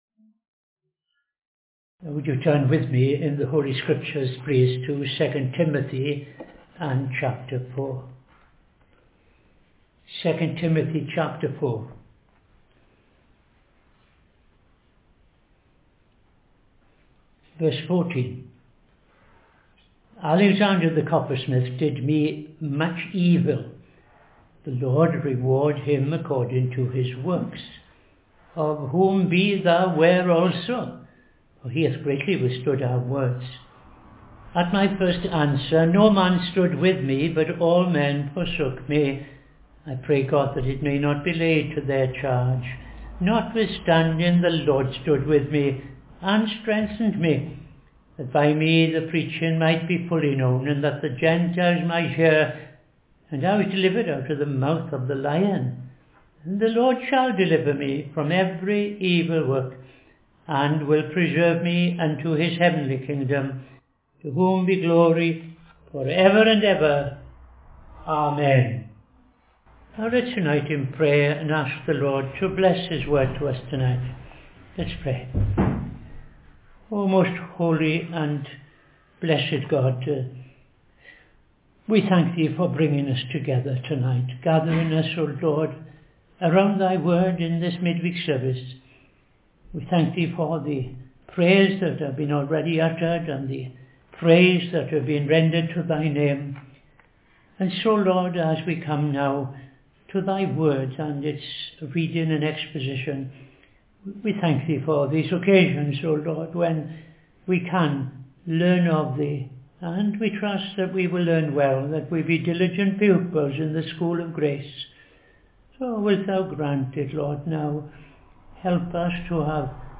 Opening Prayer Sermon Part 64 ‘Alexander the Coppersmith’ II Timothy 4:14-15